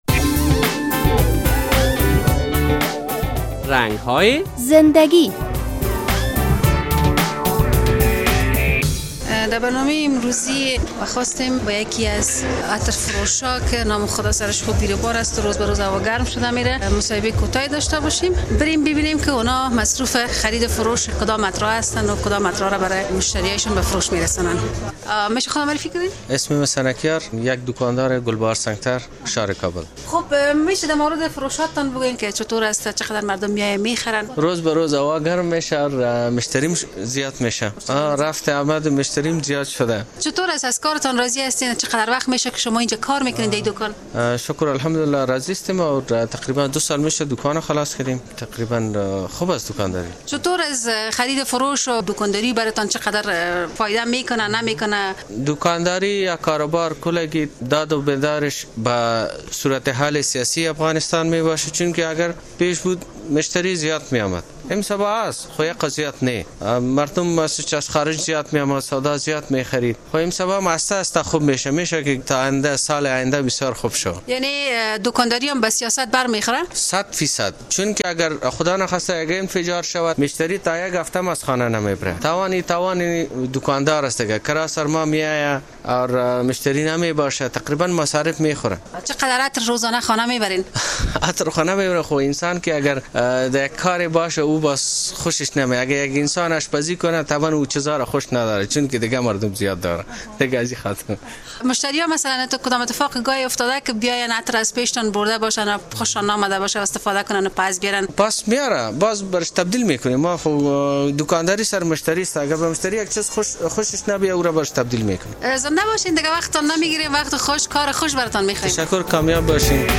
صحبتی داریم